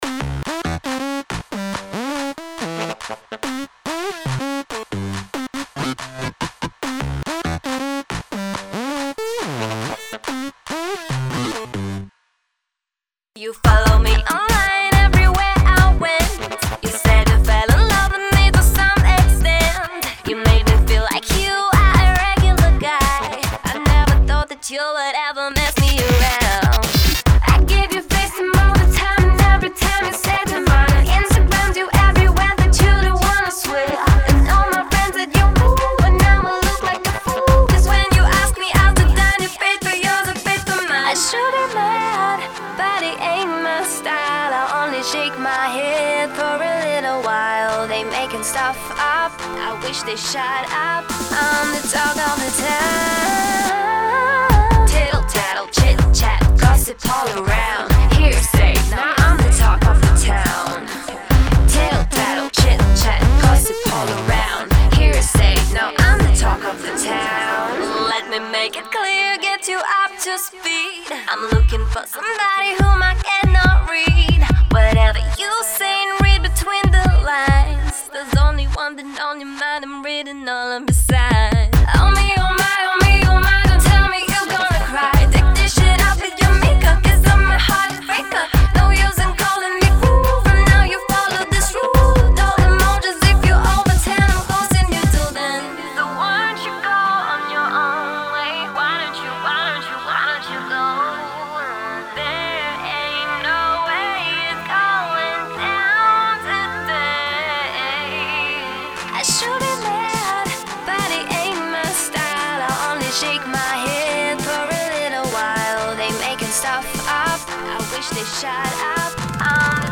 Style: EDM, Pop, Mid-tempo